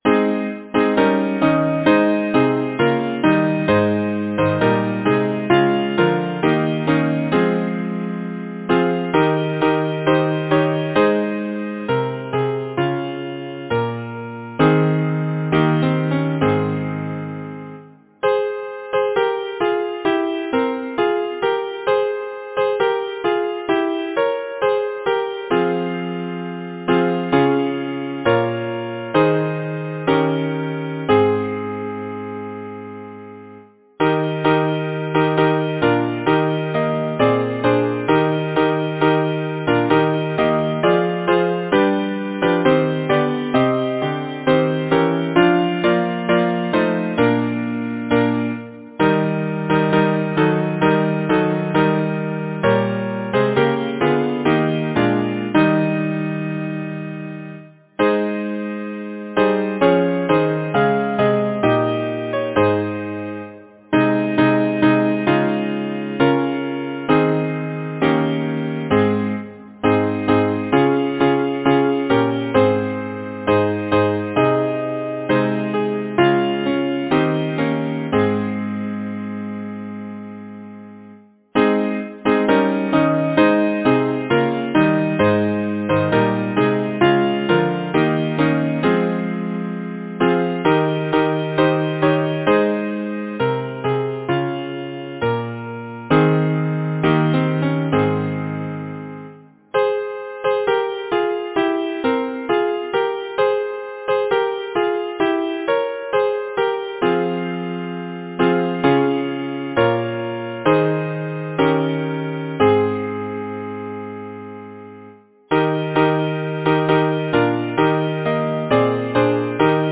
Number of voices: 4vv Voicing: SATB Genre: Secular, Partsong
Language: English Instruments: Keyboard